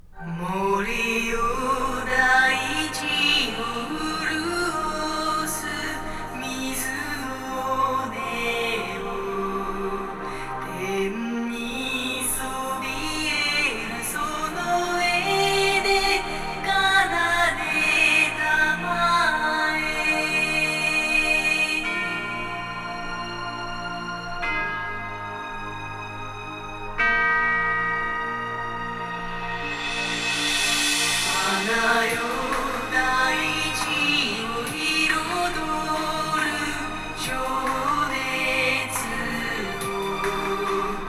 具体的にはEBU R128に基づき、−20 LUFSで正規化してみたのだ。